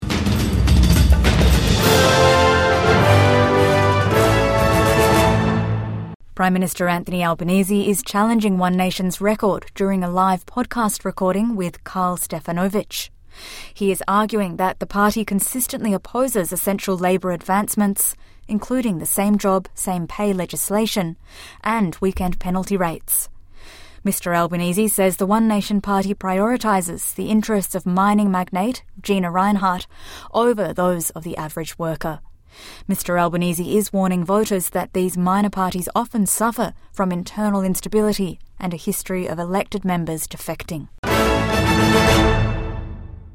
Albanese challenges One Nation’s record during a live podcast recording with Karl Stefanovic